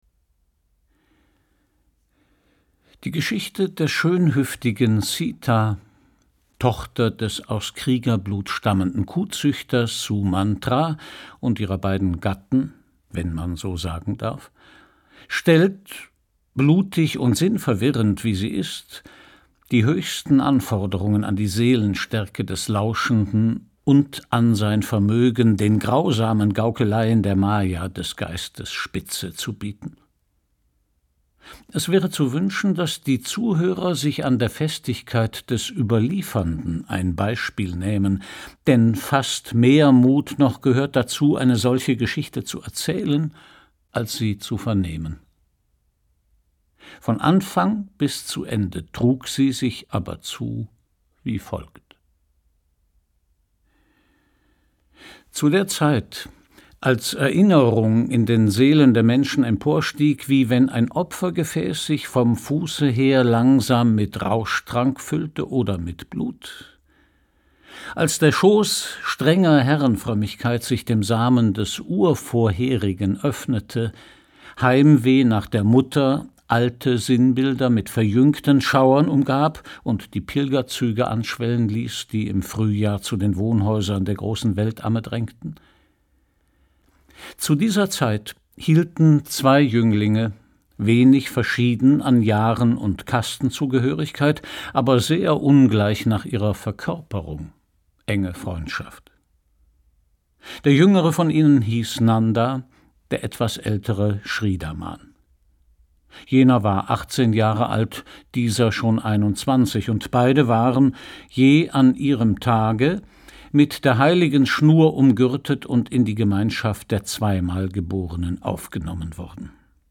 Christian Brückner (Sprecher)